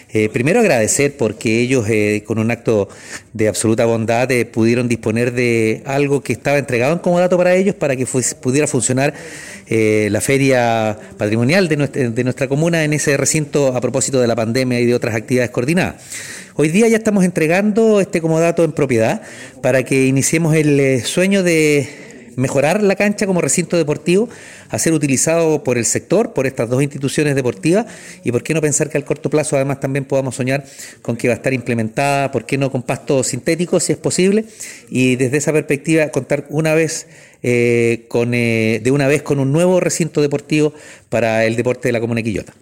Alcalde-Oscar-Calderon-Reunion-con-clubes-Manuela-Figueroa-y-Rayon-Said-1.mp3